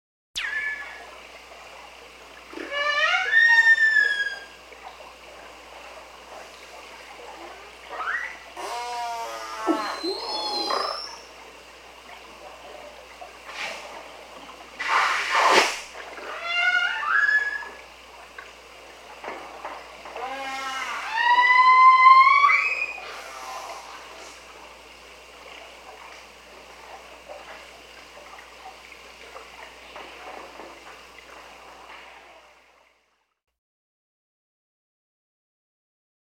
دانلود آهنگ وال 8 از افکت صوتی انسان و موجودات زنده
جلوه های صوتی
دانلود صدای وال 8 از ساعد نیوز با لینک مستقیم و کیفیت بالا
برچسب: دانلود آهنگ های افکت صوتی انسان و موجودات زنده دانلود آلبوم صدای حیوانات آبی از افکت صوتی انسان و موجودات زنده